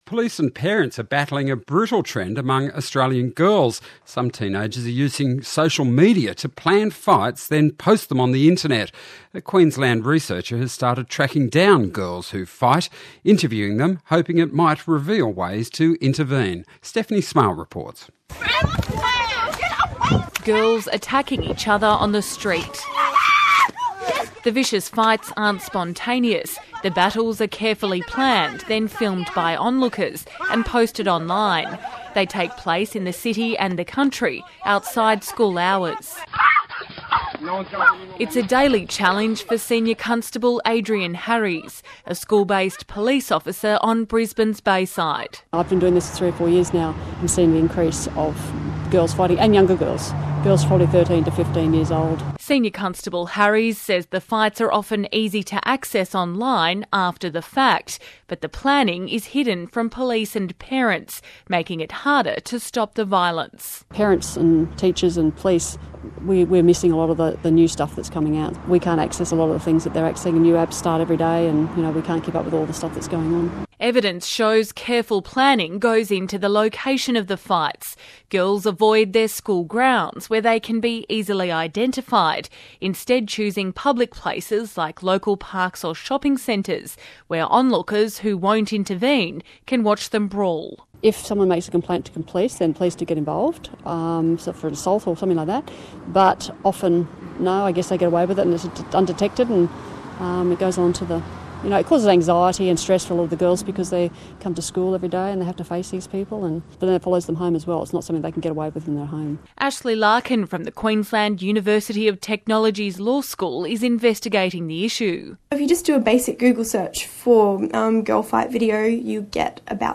IELTS HOPECO: ABC World News